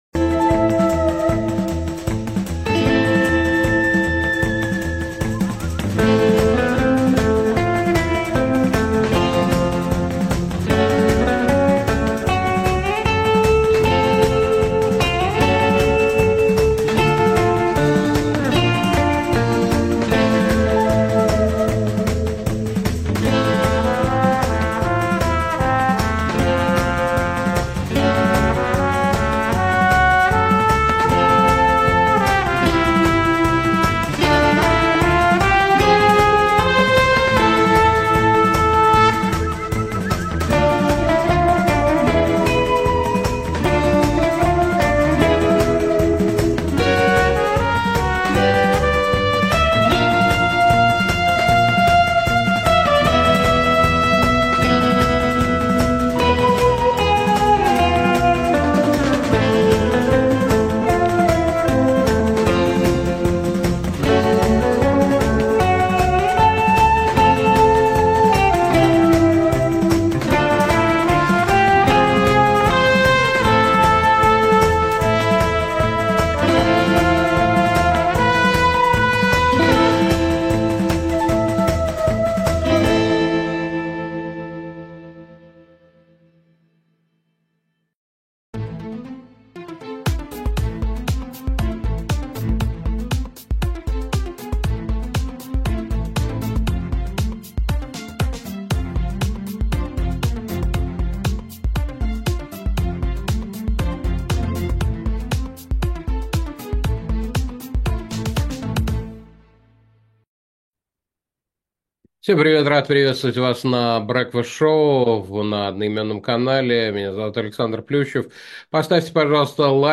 Утренний эфир с гостями